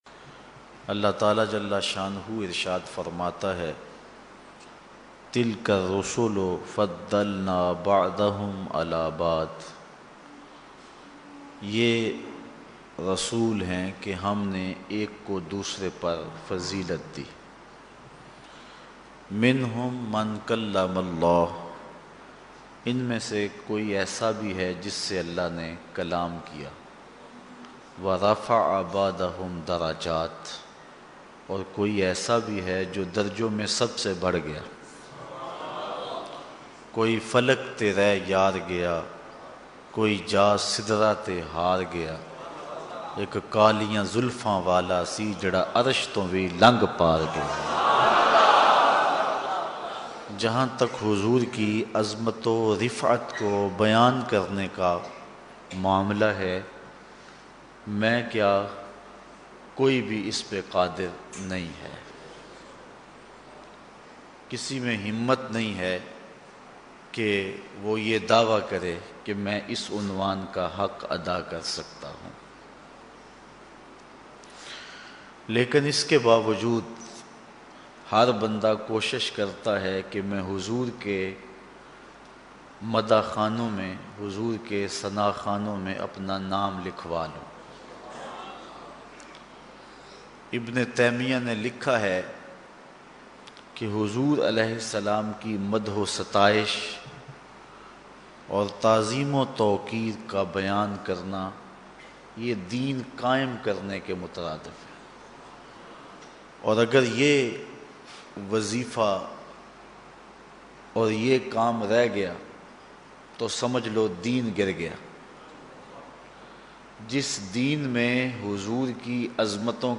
Listen online and download beautiful urdu bayan Azmat-e-Risalat-e-Maab (S.A.W.W)